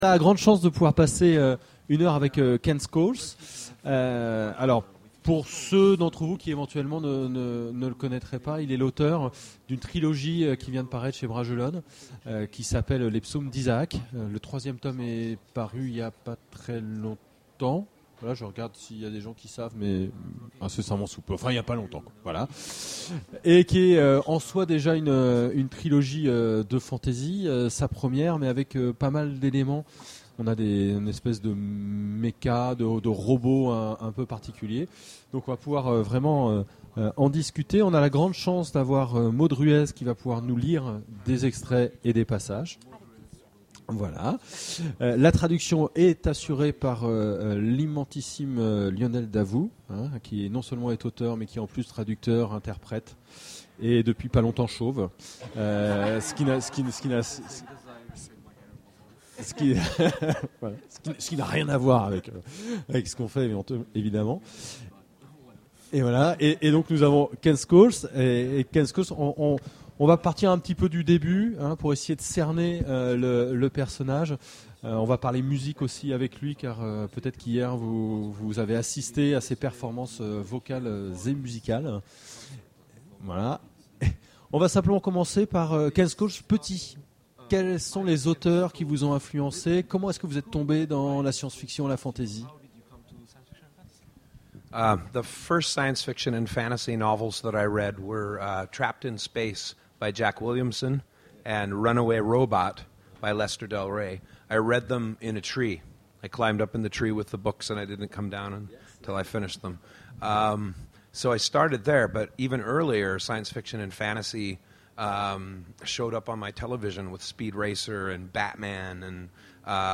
Imaginales 2013 : Conférence Entretien avec... Ken Scholes
Ken Scholes Télécharger le MP3 à lire aussi Ken Scholes Genres / Mots-clés Rencontre avec un auteur Conférence Partager cet article